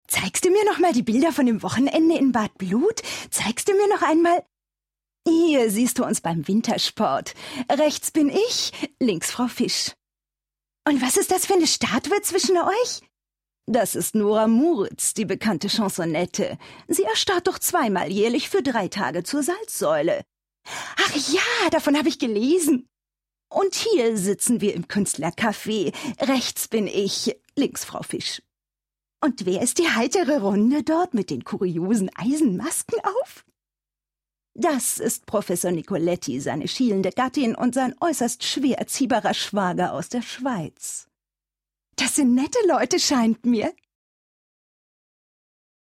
deutsche Sprecherin, Sängerin und Schauspielerin.
Sprechprobe: Werbung (Muttersprache):